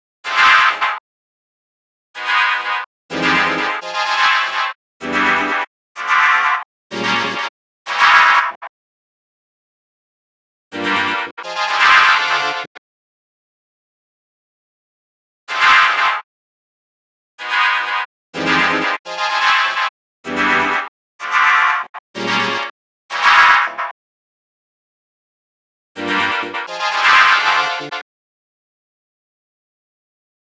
rave sequenced delayed fatti piano - Fm - 126.wav
Royalty free samples, freshly ripped from a rompler, containing sounds of the early rave and hardcore from the 90′s. These can represent a great boost to your techno/hard techno/dance production. Lively layering sounds similar to Marshall Jefferson, Deee Lite, 2 Unlimited, Dance 2 Trance, Inner City and others.
rave_sequenced_delayed_fatti_piano_-_fm_-_126_tz0.ogg